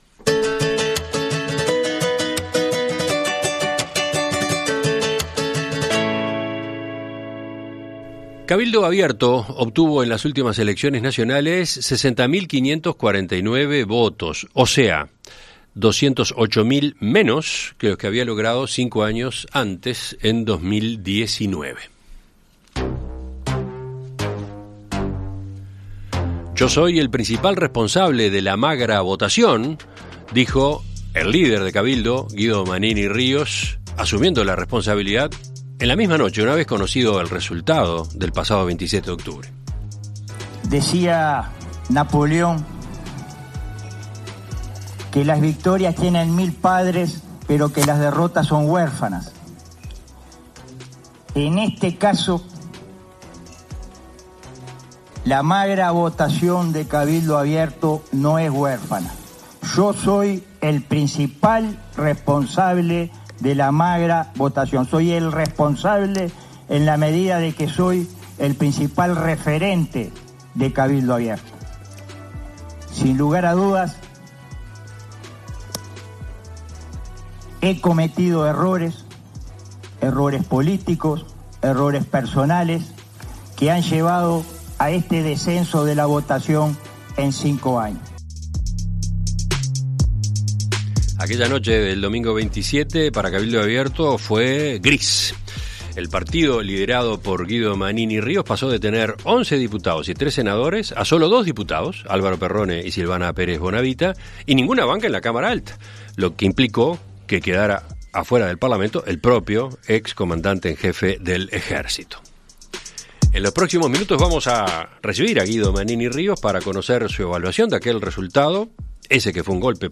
En Perspectiva Zona 1 – Entrevista Central: Guido Manini Ríos - Océano